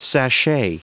Prononciation du mot sachet en anglais (fichier audio)
Prononciation du mot : sachet